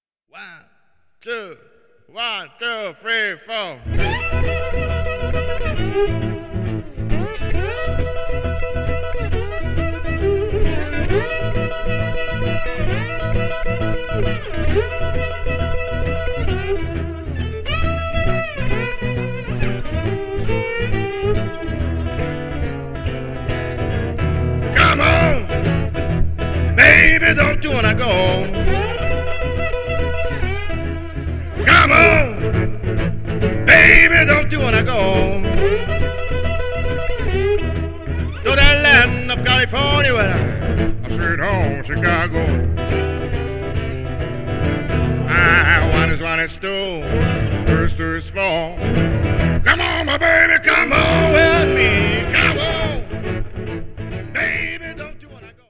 violin
vocal, guitar, dobro, slide
guitar, harmonica, vocal